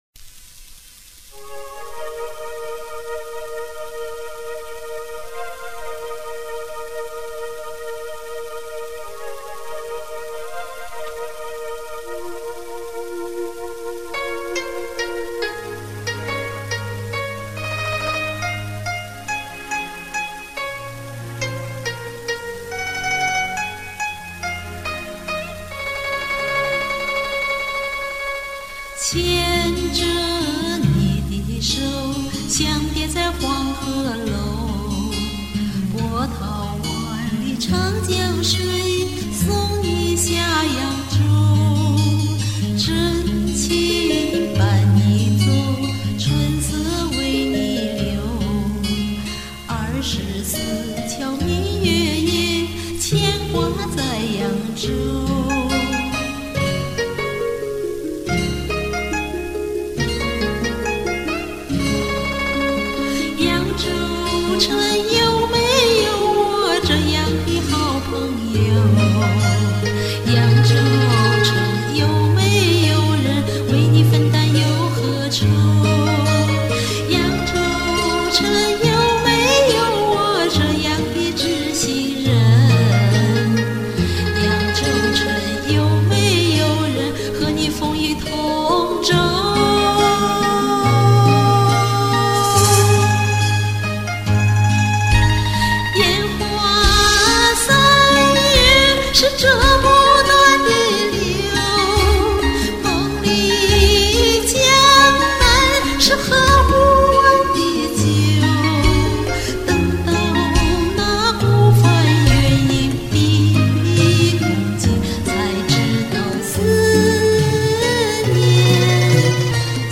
好听的江南风格的歌，好唱